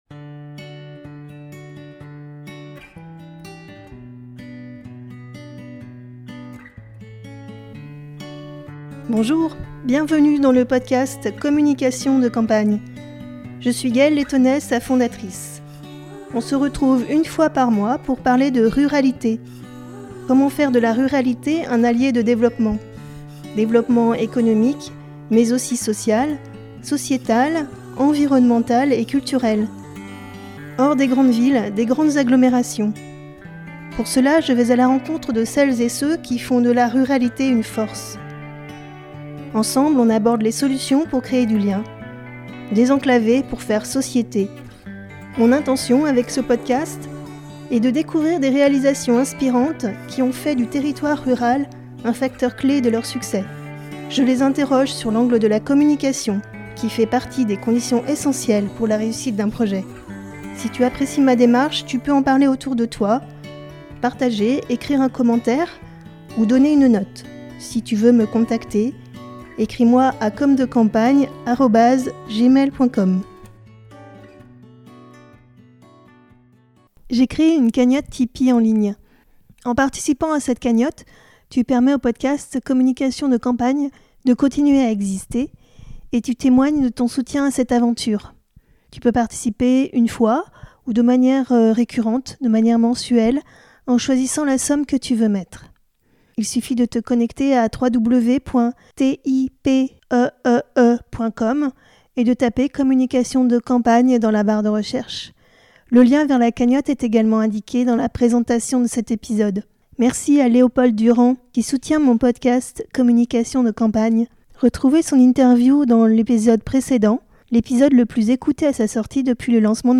Communication de campagne Interview